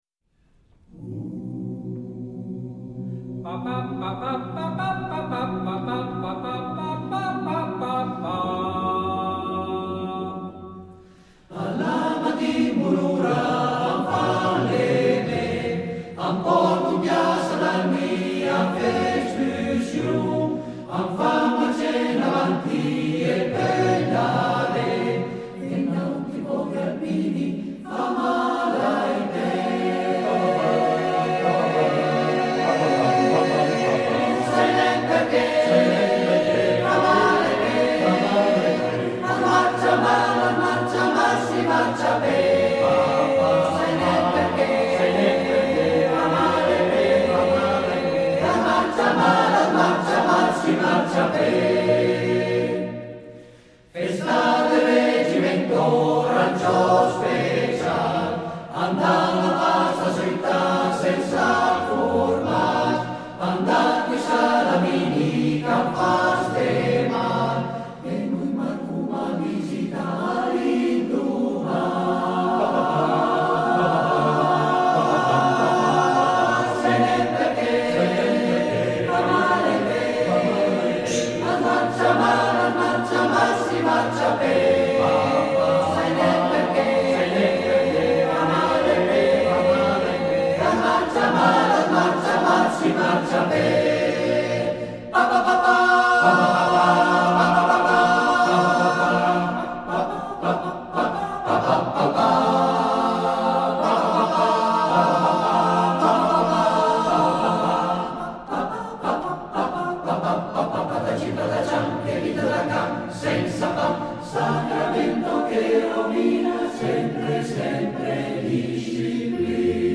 Esecutore: Coro Edelweiss